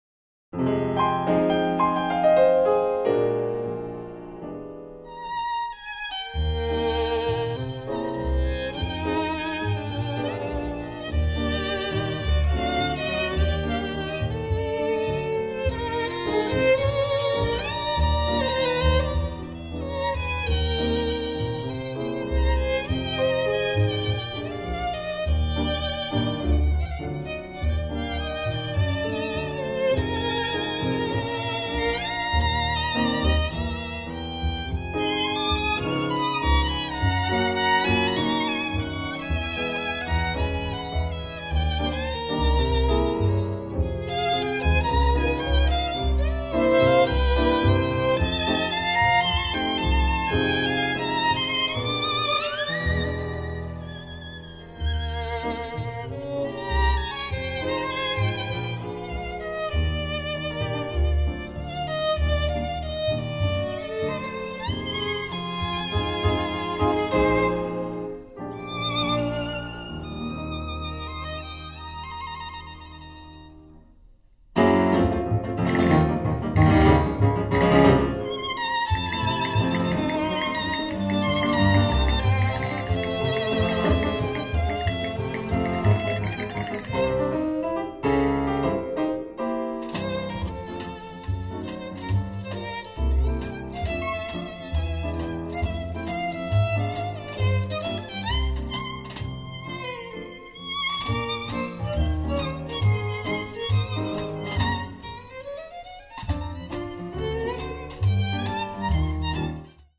★ 具有深厚古典音樂基礎、詮釋探戈與沙龍小品音樂聲名大噪之加拿大合奏樂團之精湛演出！
★ 多首精緻沙龍小品、充分展現舞曲節奏與快感！ ★ 優異錄音完美捕捉最真實的臨場感，不敗經典！
在精緻而充滿活力的合奏中，充分展現舞曲的節奏與快感，雅俗共賞、暢快無比；錄音忠實呈現樂器質感，如臨現場的室內樂演奏